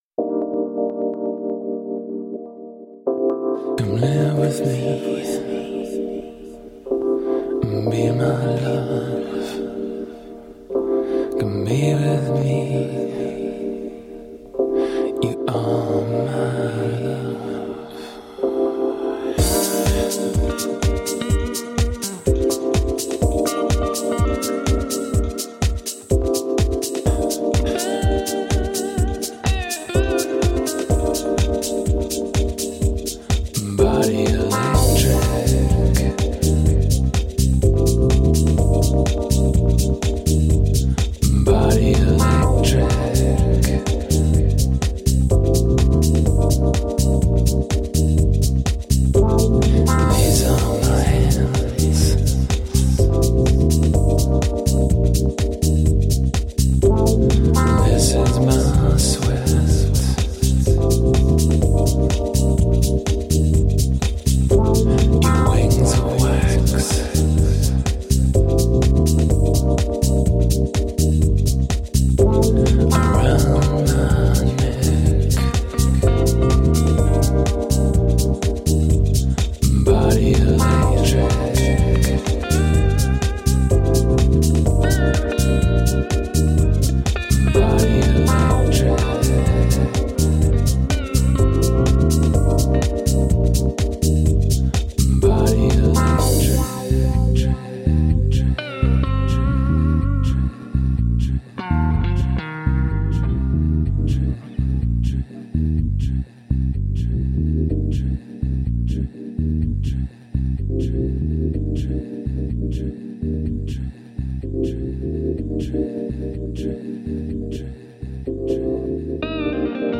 Downtempo groovy electro-pop.